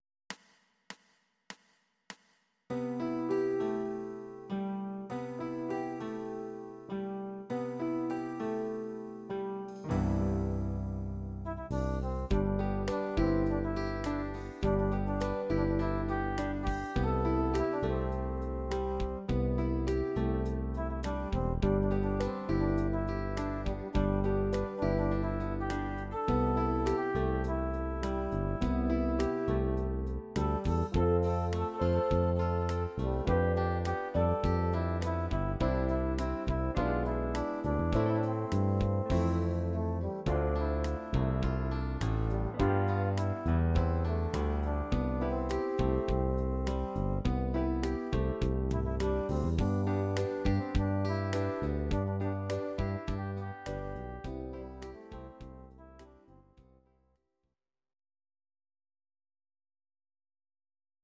These are MP3 versions of our MIDI file catalogue.
Please note: no vocals and no karaoke included.
Your-Mix: Country (821)